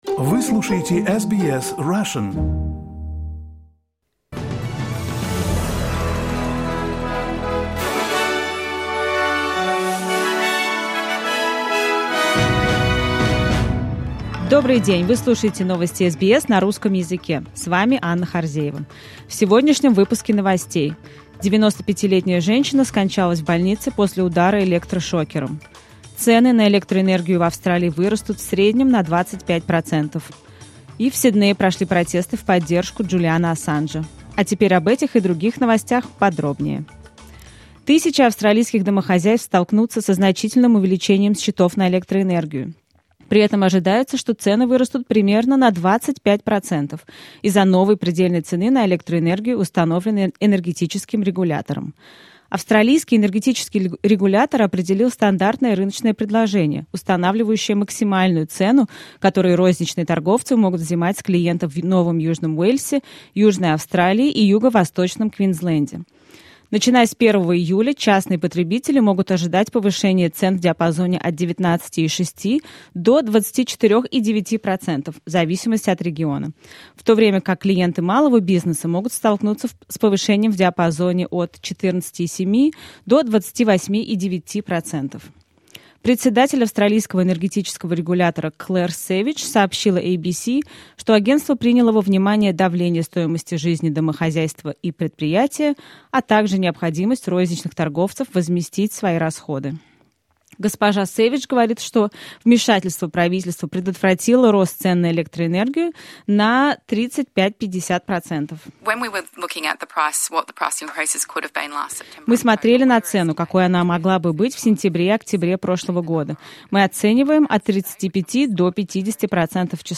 SBS news in Russian — 25.05.2023